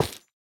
Minecraft Version Minecraft Version latest Latest Release | Latest Snapshot latest / assets / minecraft / sounds / block / nether_wood_hanging_sign / break2.ogg Compare With Compare With Latest Release | Latest Snapshot
break2.ogg